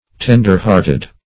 Tender-hearted \Ten"der-heart`ed\, a.